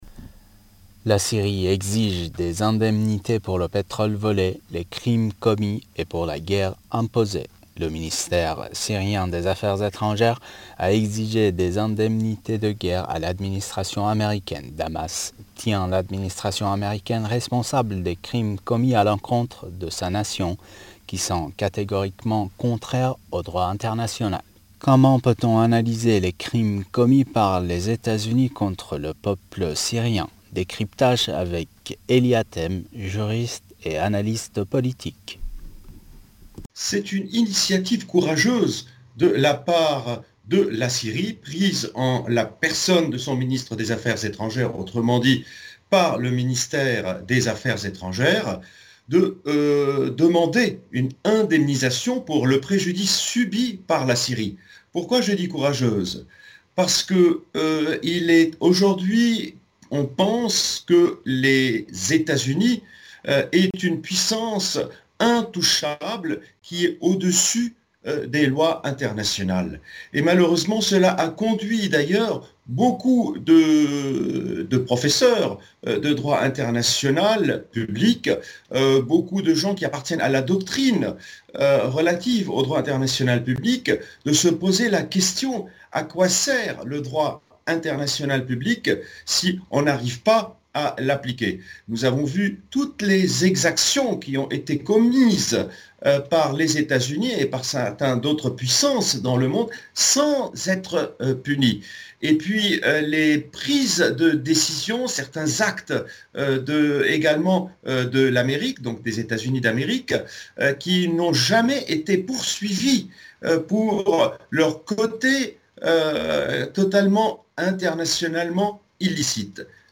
juriste international, nous répond.